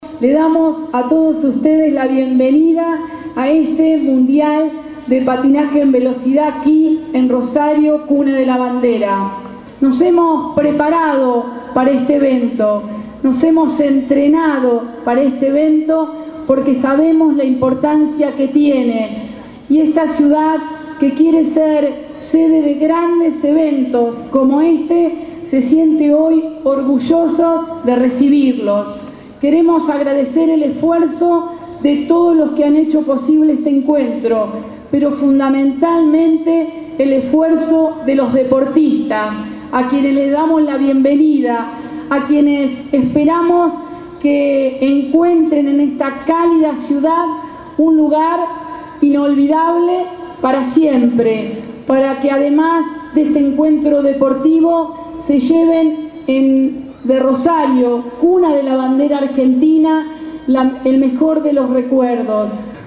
El gobernador Antonio Bonfatti y la intendenta de Rosario, Mónica Fein, encabezaron en el Monumento Nacional a la Bandera, la apertura oficial del Mundial de Patinaje de Velocidad que se desarrollará hasta el 15 de noviembre en la ciudad, con la presencia de más de 400 competidores de 33 países.
En tanto, Fein les dio la “bienvenida a la Cuna de la Bandera” a los atletas, y señaló que “el deporte integra y permite profundizar los vínculos sociales”.